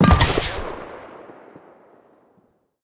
smoke_grenade_release.ogg